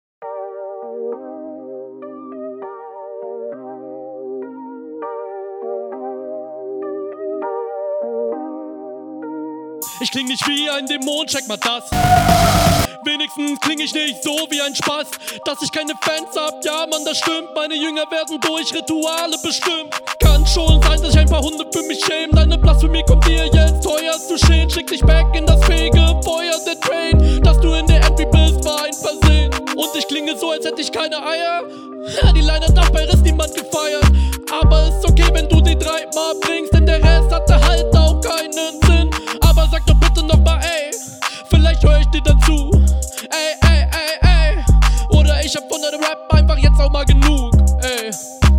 Sound klingt sehr viel weniger smooth, die stimme klingt sehr instabil.